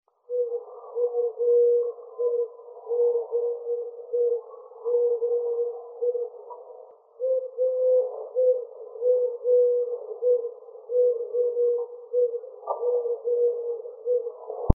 Tórtola Turca (Streptopelia decaocto)
Nombre en inglés: Eurasian Collared Dove
Fase de la vida: Adulto
Localidad o área protegida: Cambridge
Condición: Silvestre
Certeza: Fotografiada, Vocalización Grabada
Collared-Dove-.MP3